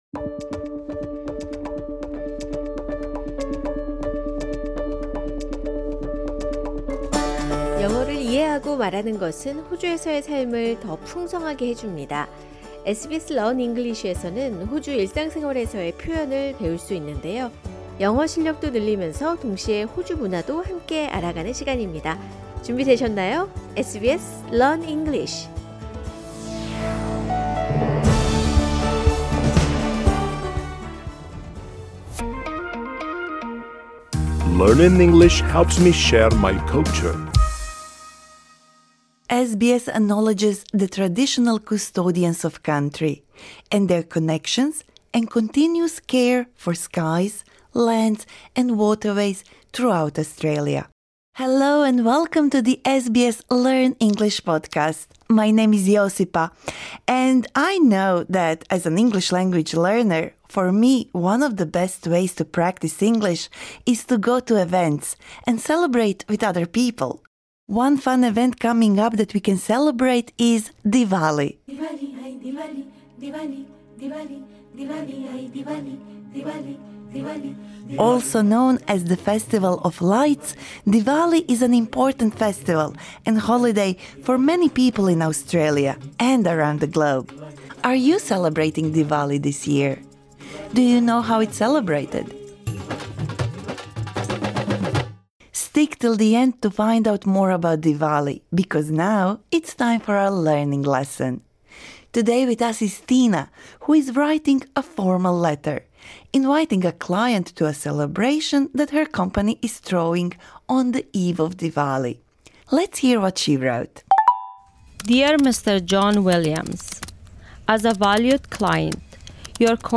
SBS Learn English will help you speak, understand and connect in Australia - view all episodes This lesson suits upper-intermediate to advanced learners.